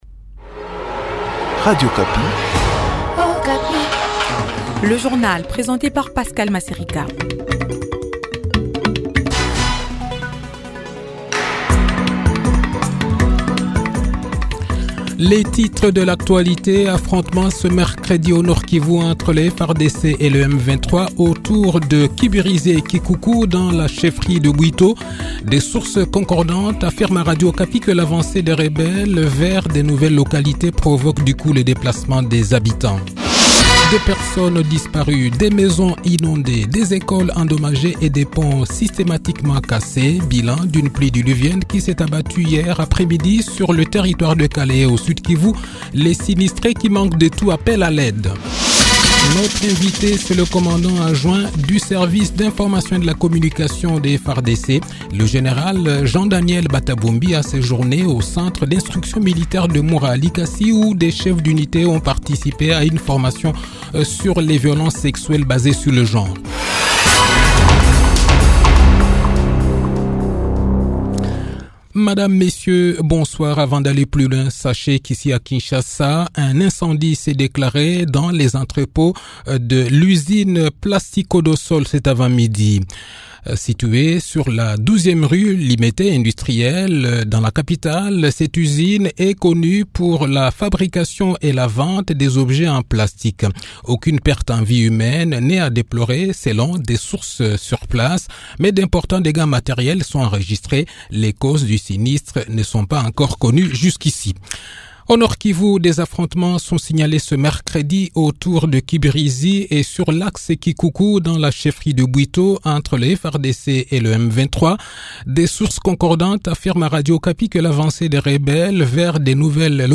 Le journal de 18 h, 6 mars 2024